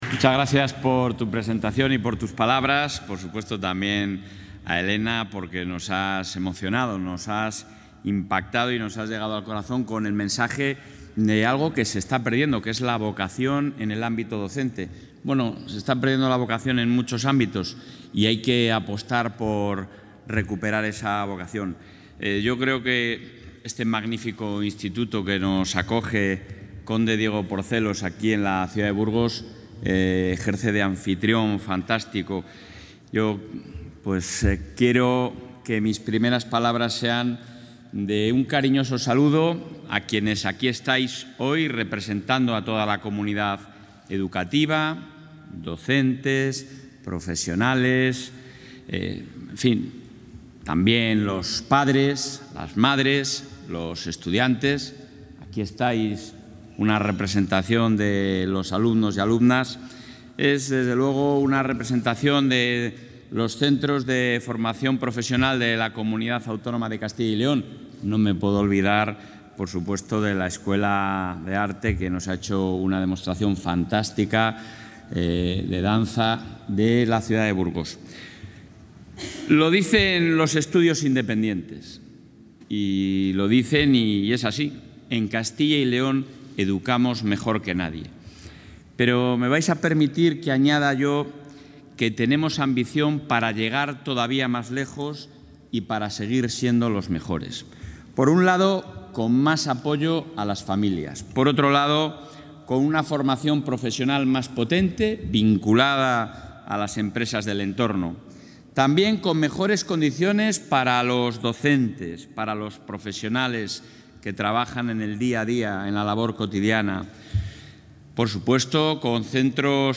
Intervención del presidente de la Junta.
Durante la inauguración del curso 2022-2023 de las enseñanzas escolares de Castilla y León celebrado esta mañana en IES Conde Diego Porcelos, en Burgos, el presidente de la Junta de Castilla y León, Alfonso Fernández Mañueco, ha anunciado para esta legislatura un aumento de las ayudas a las familias para hacer frente a los gastos escolares ante la situación económica.